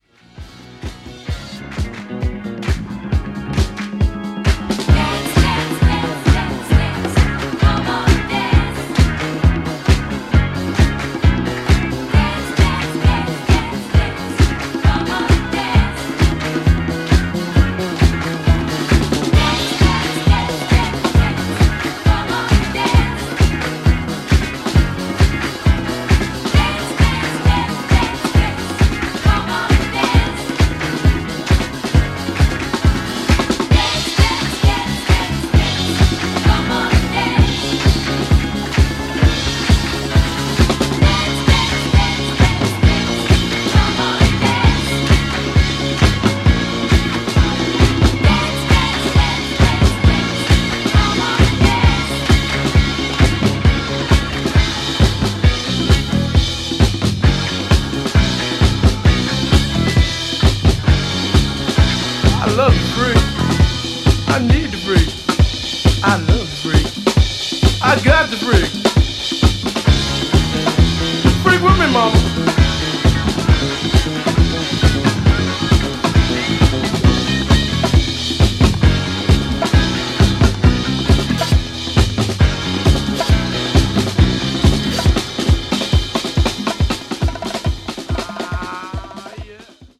New Release Disco Classics Soul / Funk